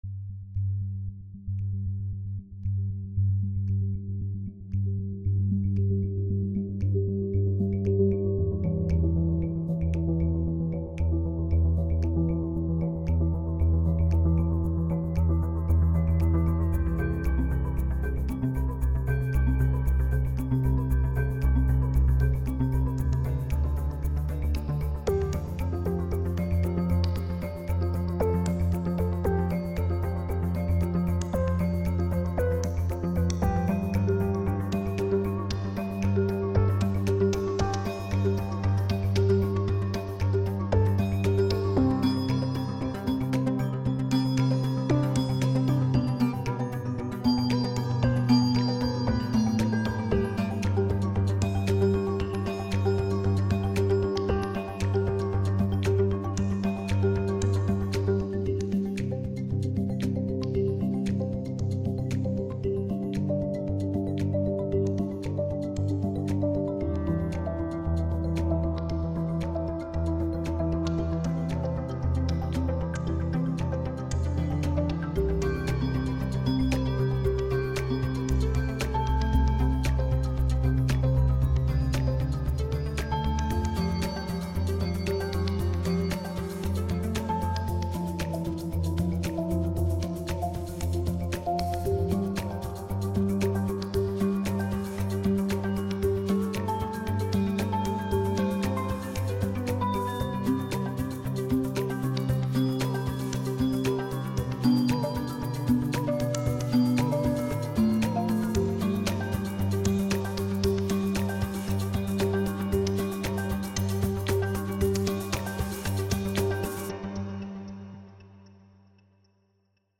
Game music